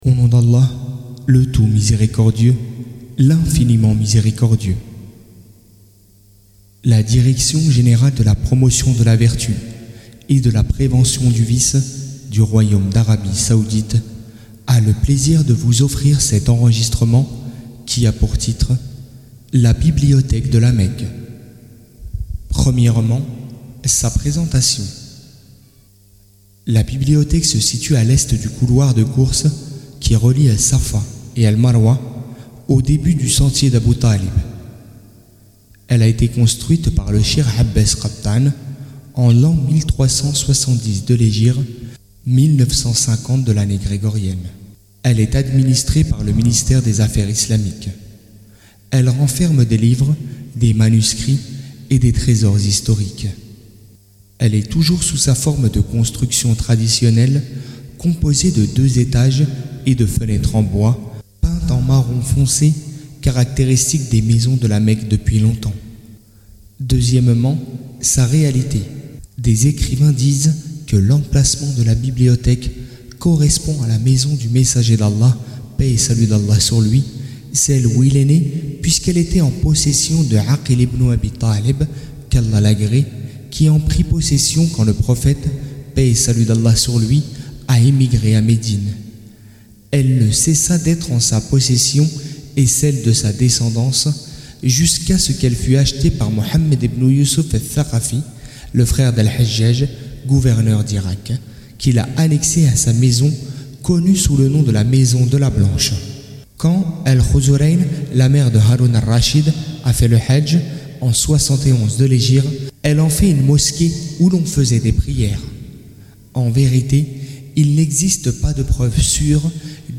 Livre audio: La Bibliothèque de La Mecque